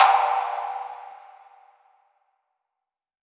WATERTANKH.wav